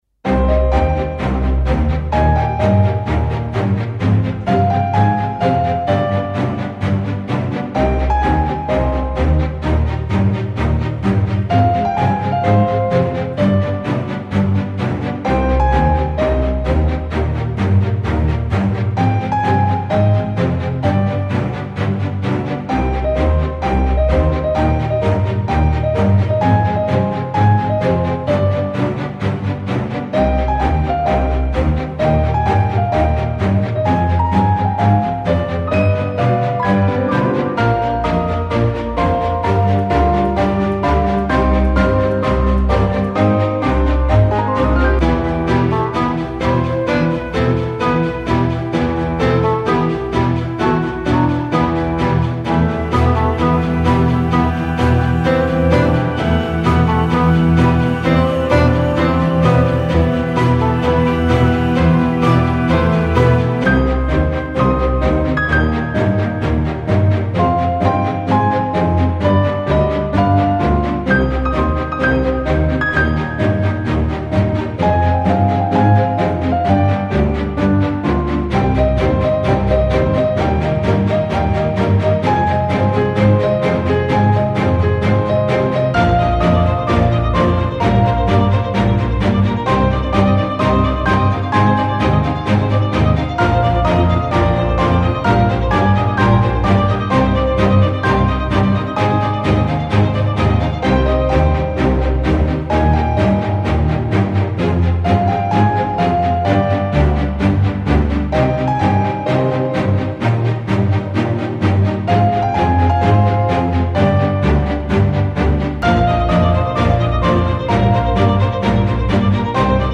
historique - nostalgique - violons - piano - pizzicati